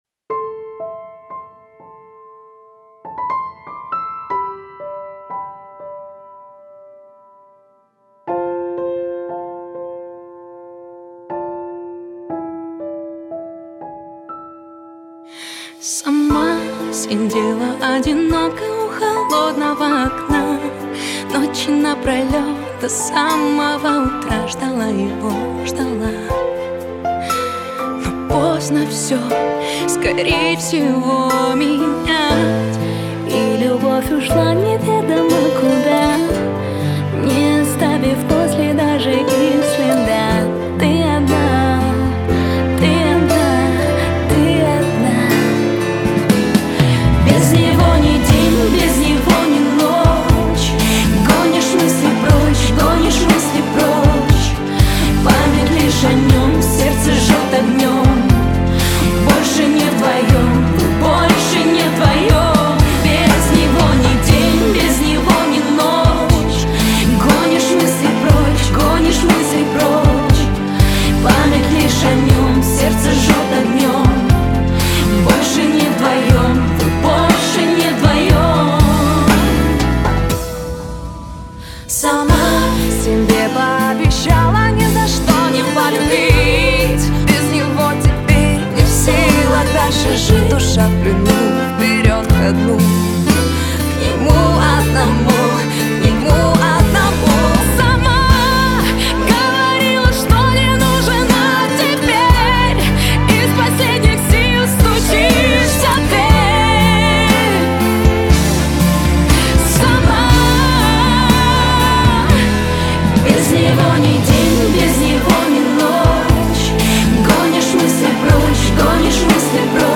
Категория: Грустные песни